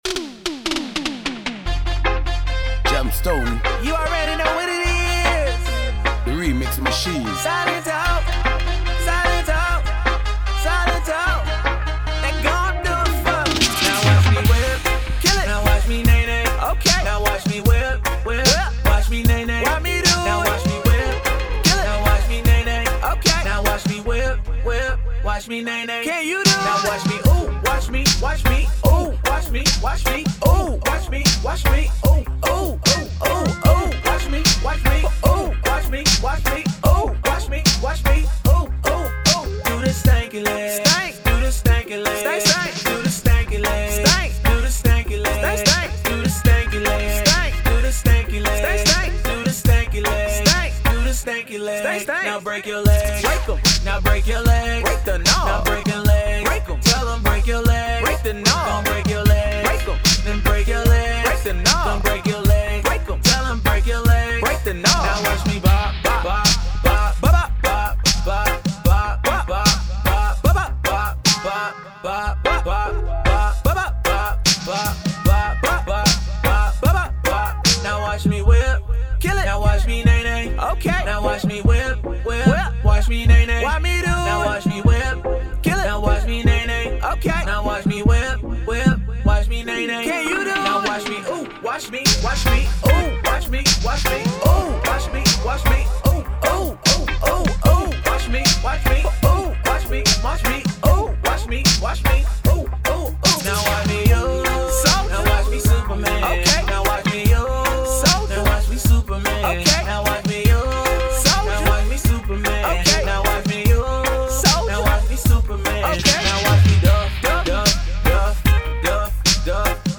Legal riddim